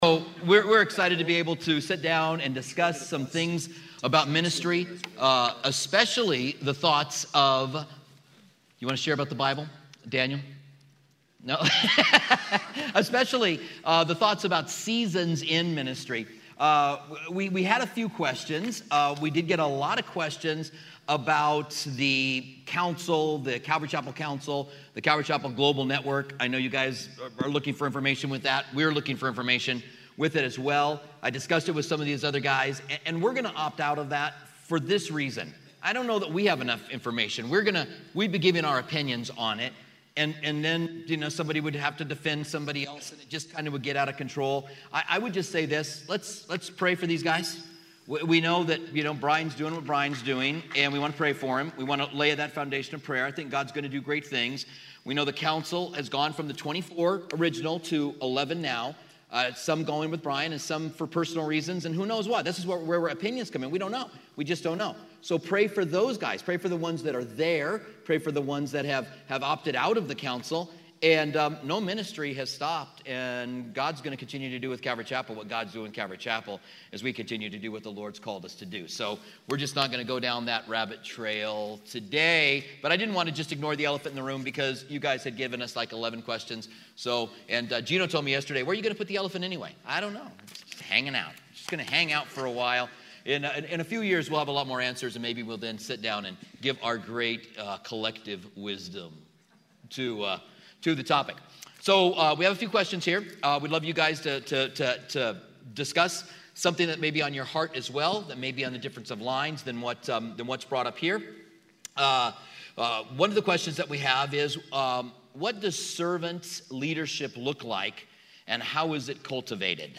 Seasons of a Shepherd: Pastoral Panel Mar 7, 2017 · undefined Listen to the Pastoral Panel Q&A from the 2017 Southwest Pastors and Leaders Conference, Seasons of a Shepherd.